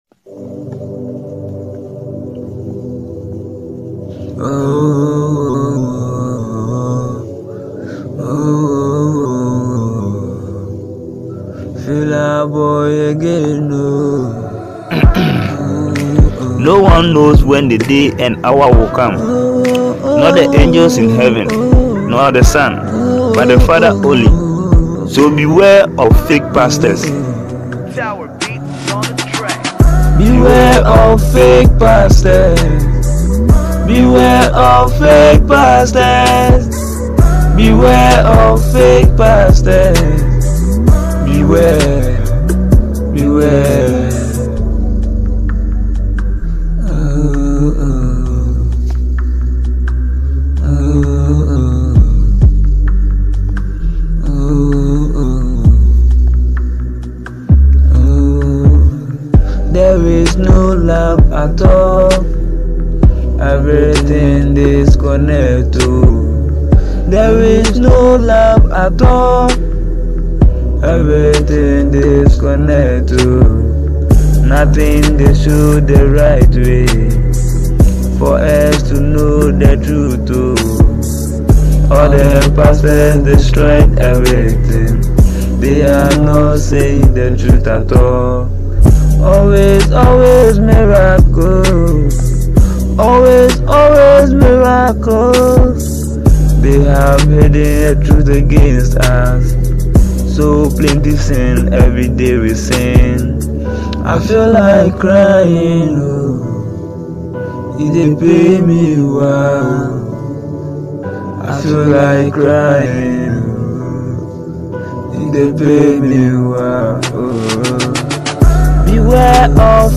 inspirational and motivational track